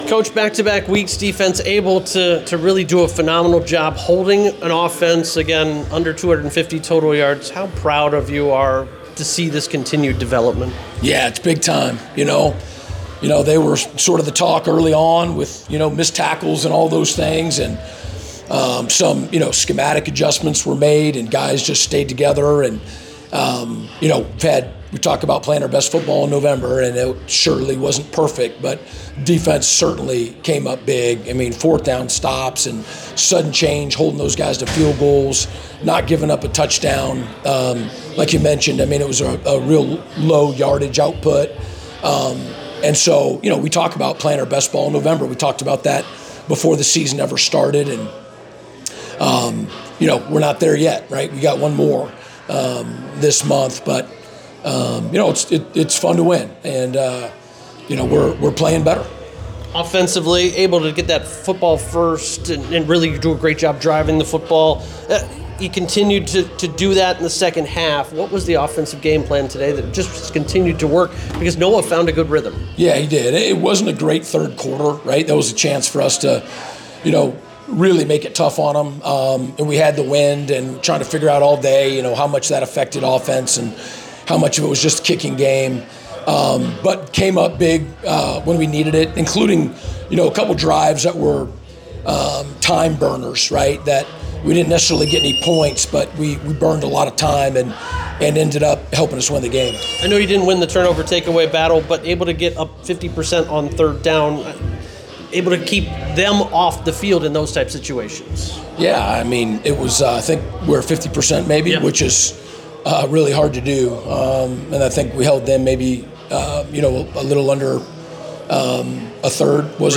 Post Game Presser